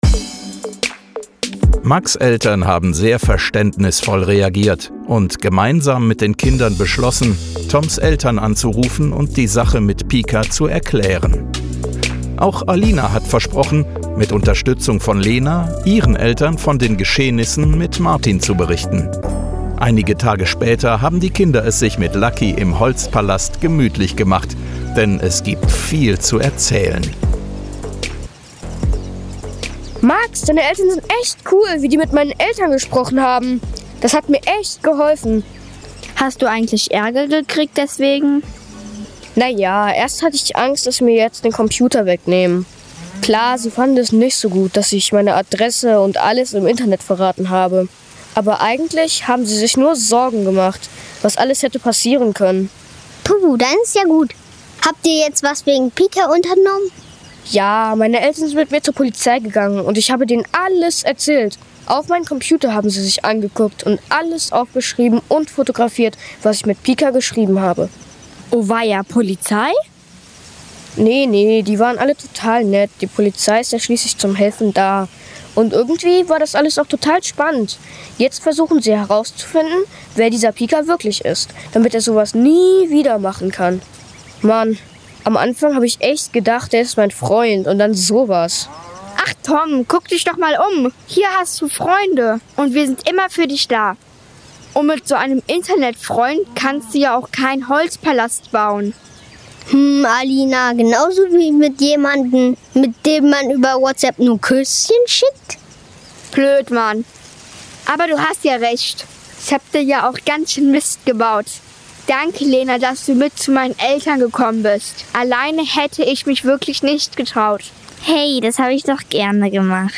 Das Hörspiel der Zentralen Prävention des Polizeipräsidiums Koblenz ist exklusiv hier abrufbar!